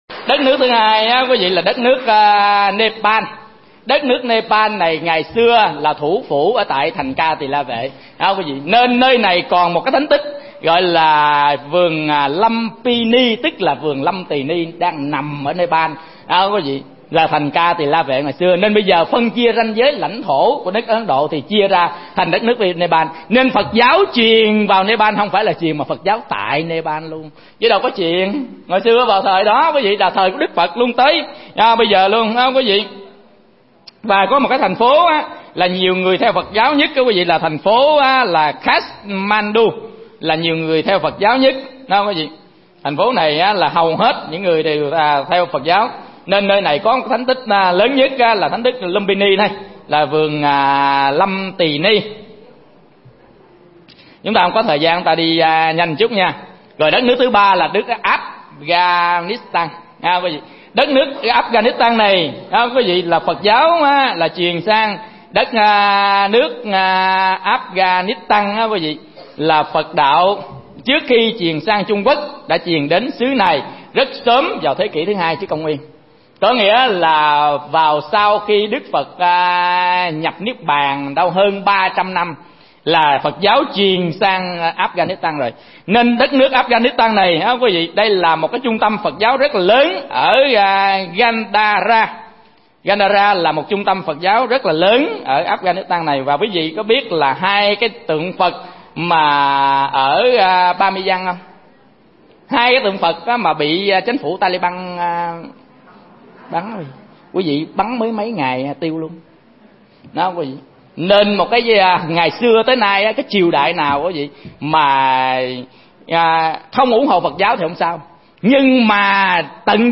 Tải mp3 Thuyết Pháp Phật Giáo Thế Giới 2